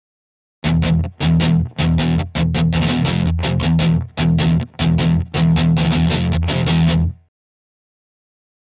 Guitar Slow Heavy Metal Rhythm - Long